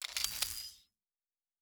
pgs/Assets/Audio/Sci-Fi Sounds/Weapons/Weapon 16 Reload 2 (Laser).wav at master
Weapon 16 Reload 2 (Laser).wav